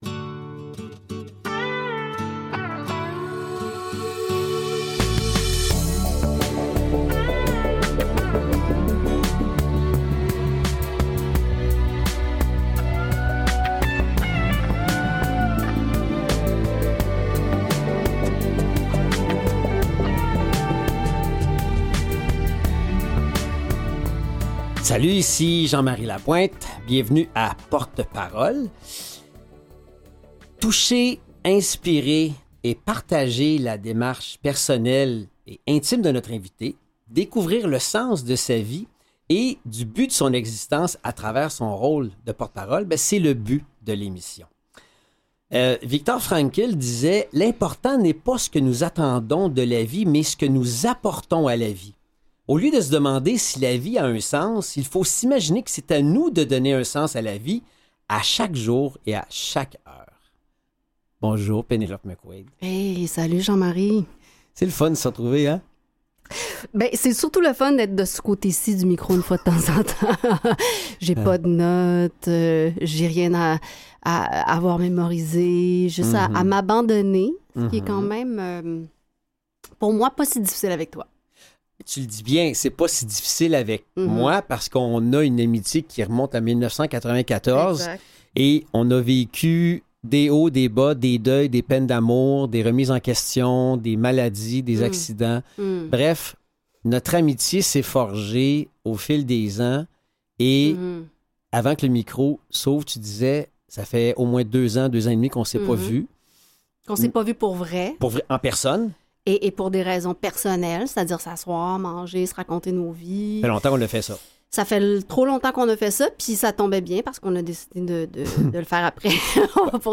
Victime d’un grave accident de la route, Pénélope McQuade a été soignée en traumatologie à l’Hôpital Sacré-Cœur-de-Montréal, où elle a également fait sa réhabilitation. Pénélope McQuade en est devenue la porte-parole depuis plus de cinq ans. Animateur et recherchiste : Jean-Marie Lapointe Mise en ondes
Invitée : Pénélope McQuade, animatrice